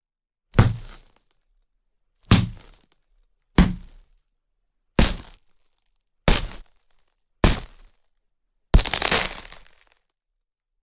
Стекло звуки скачать, слушать онлайн ✔в хорошем качестве